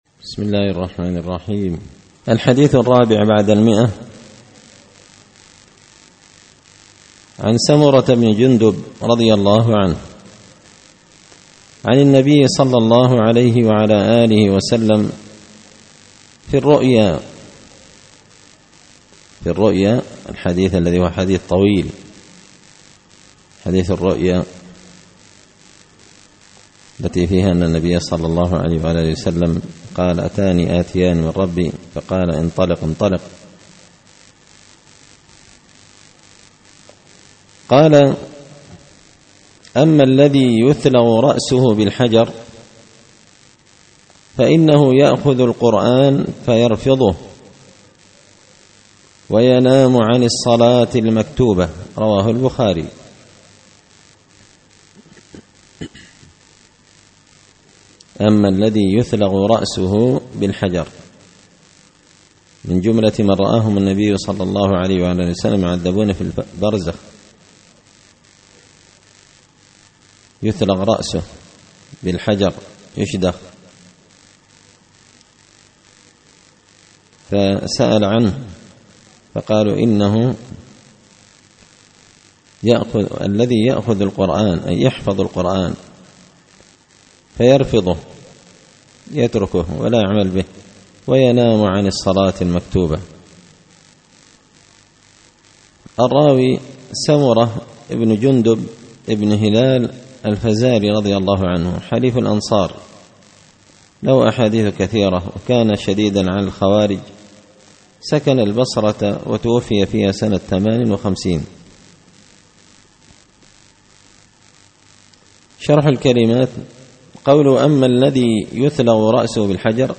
الأحاديث الحسان فيما صح من فضائل سور القرآن ـ الدرس السبعون
دار الحديث بمسجد الفرقان ـ قشن ـ المهرة ـ اليمن